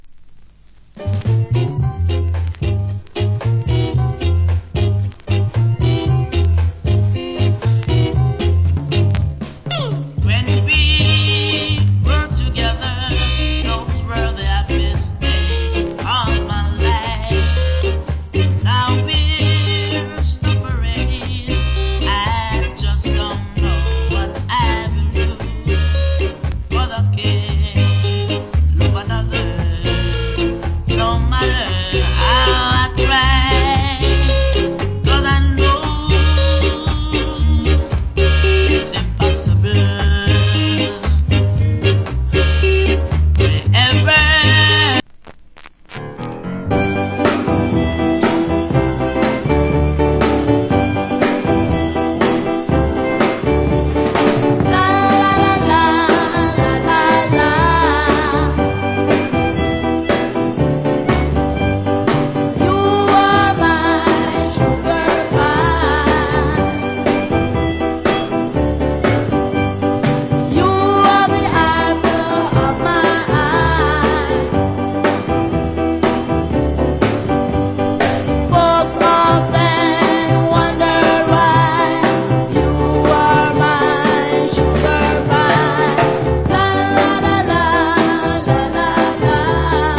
Nice Rock Steady Vocal.